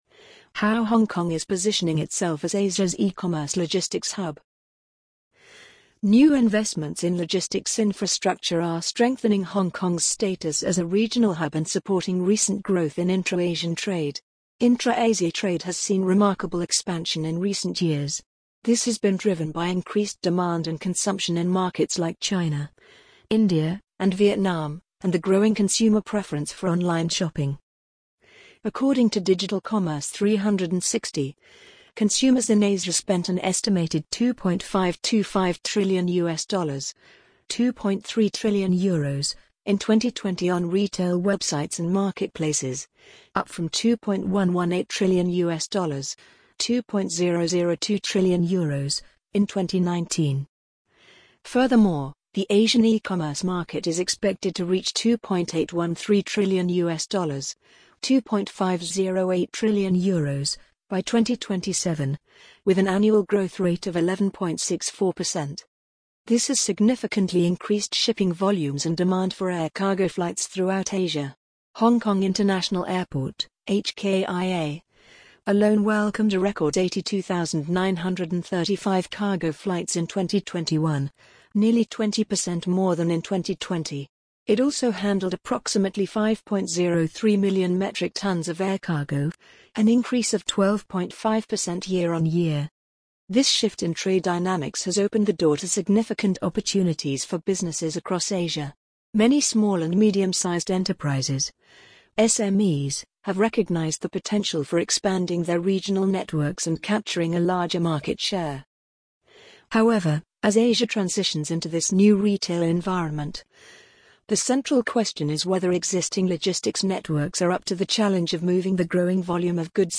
amazon_polly_47143.mp3